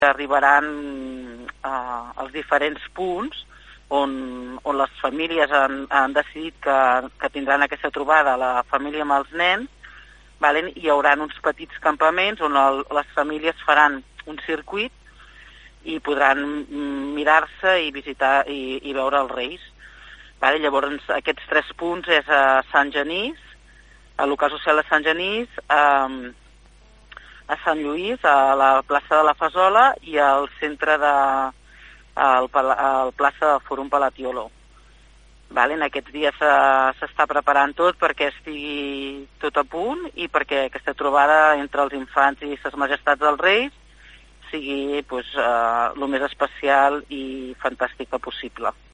En parla Susanna Pla, 1a Tinent d’alcalde i regidora de Cultura del consistori palafollenc.